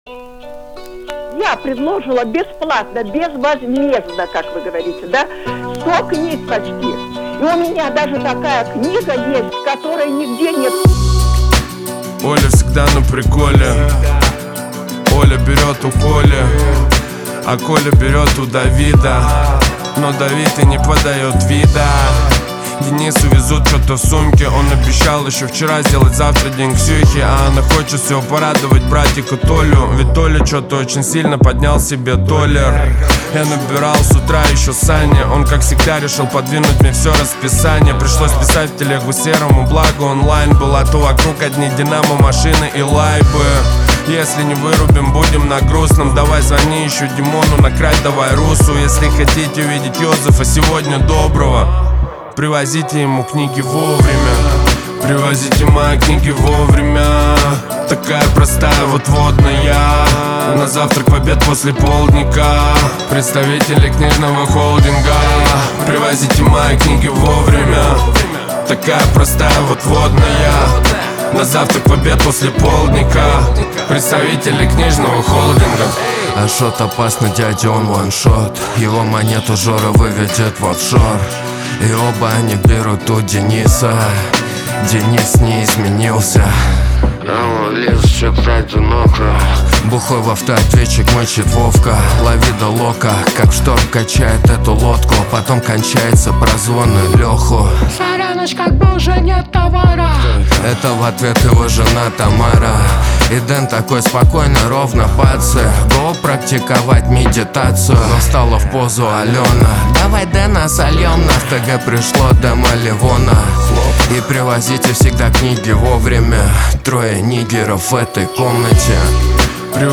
Трек размещён в разделе Русские песни / Альтернатива.